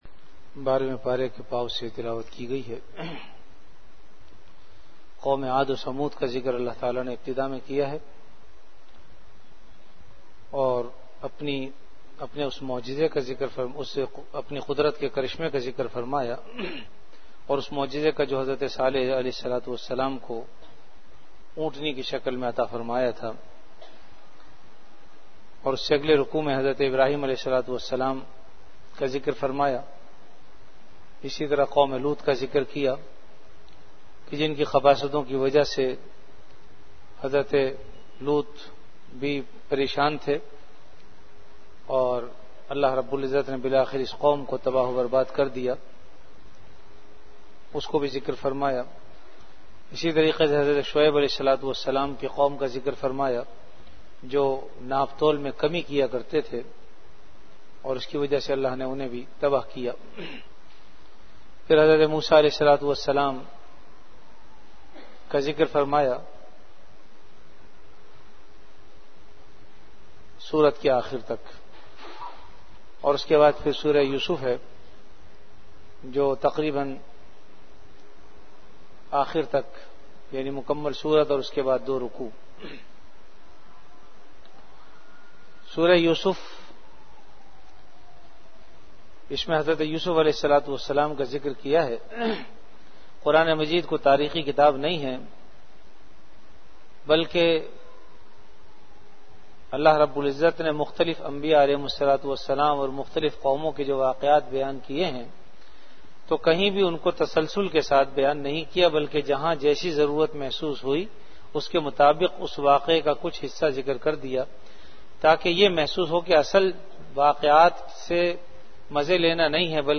Ramadan - Taraweeh Bayan · Jamia Masjid Bait-ul-Mukkaram, Karachi